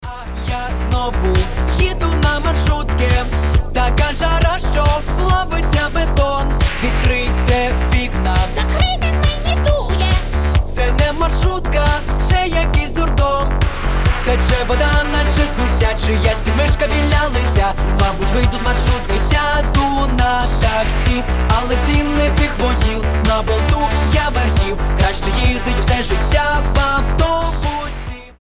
• Качество: 320, Stereo
веселые
Cover
смешные
пародии